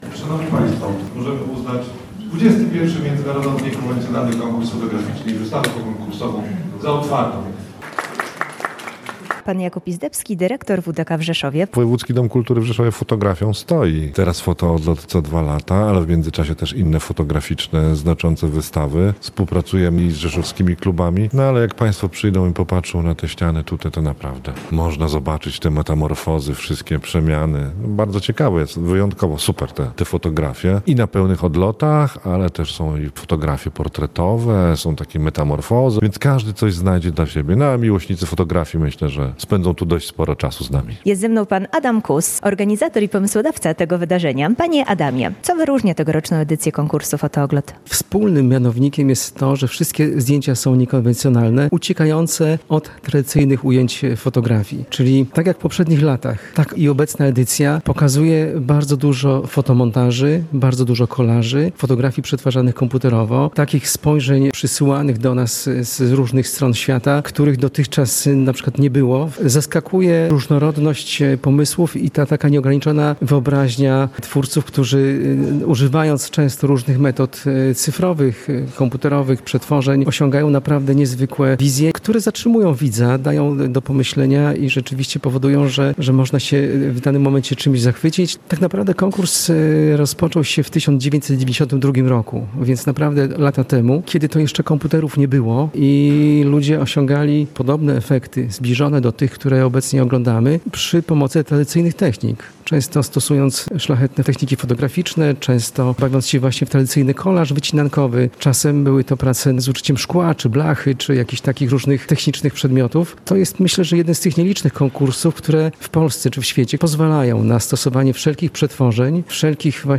Foto-Odlot-RelacjaOK.mp3